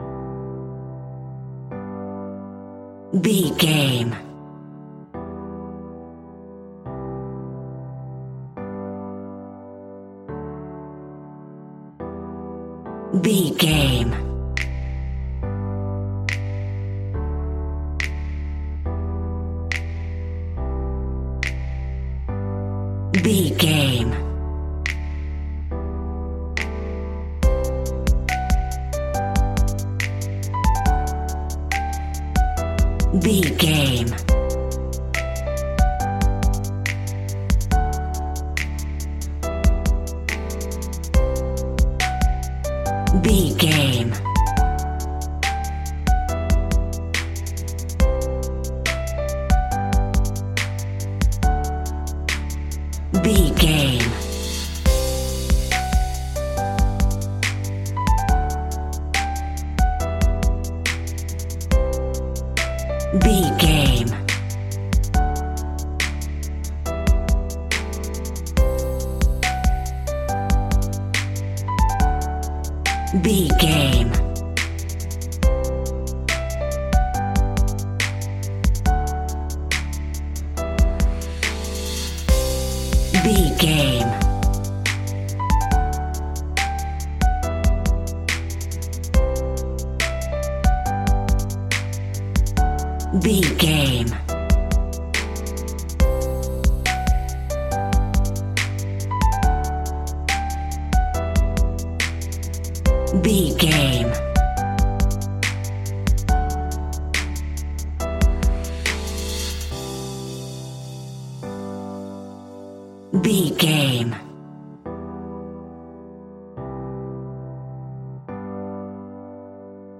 Ionian/Major
Slow
chilled
laid back
groove
hip hop drums
hip hop synths
piano
hip hop pads